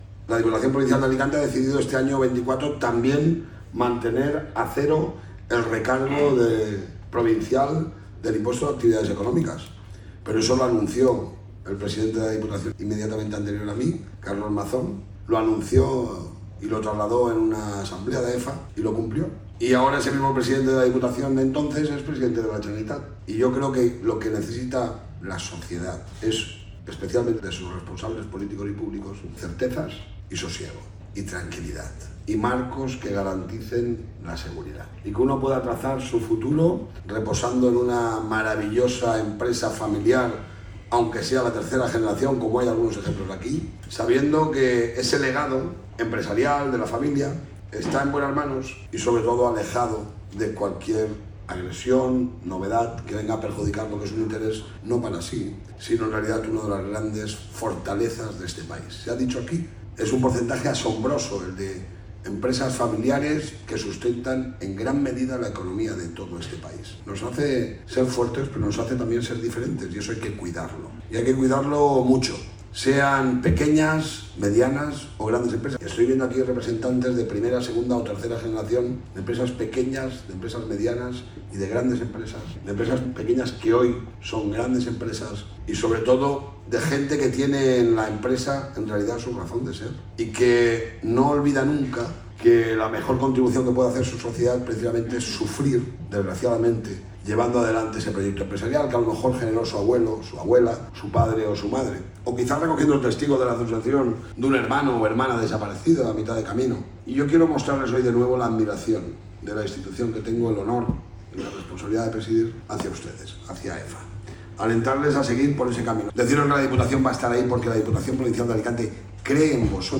El presidente de la Diputación participa en el III Encuentro Provincial de la Empresa Familiar organizado por AEFA